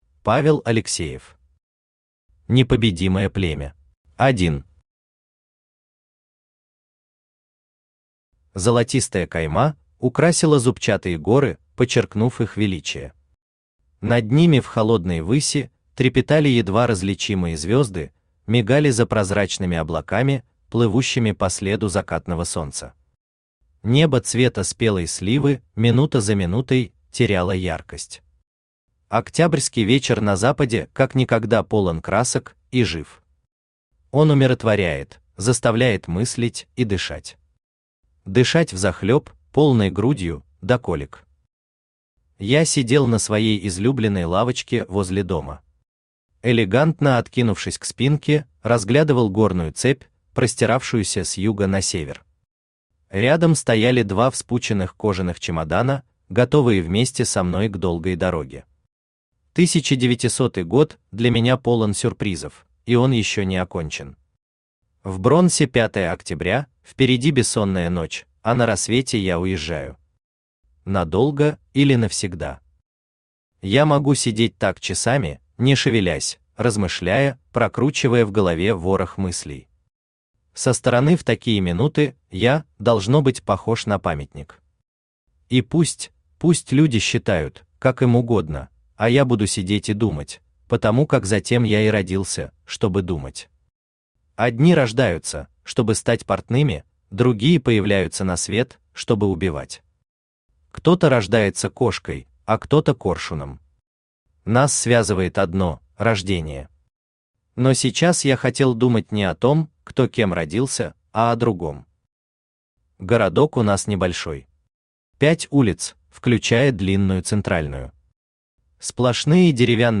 Аудиокнига Непобедимое племя | Библиотека аудиокниг
Aудиокнига Непобедимое племя Автор Павел Николаевич Алексеев Читает аудиокнигу Авточтец ЛитРес.